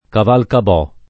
[ kavalkab 0+ ]